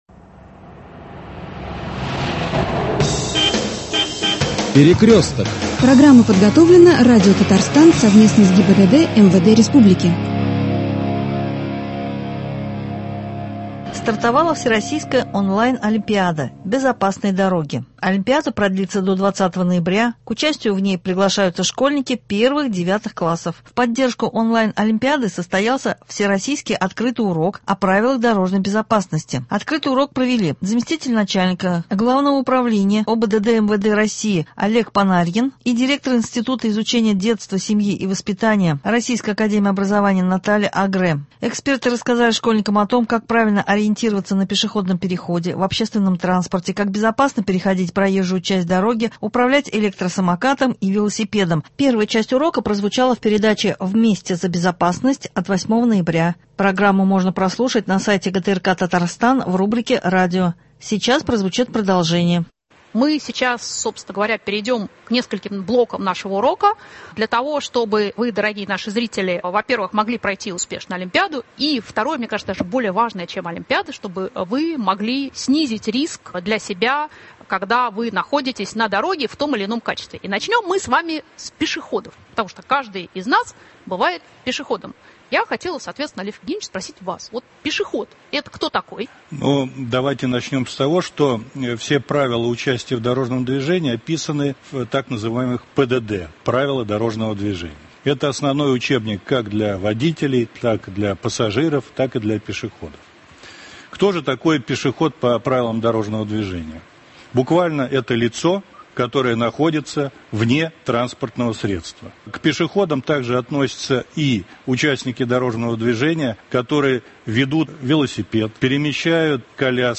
1 часть урока прозвучала в передаче «Вместе за безопасность» от 8 ноября, программу можно прослушать на сайте ГТРК Татарстан, сейчас сегодня прозвучит продолжение урока.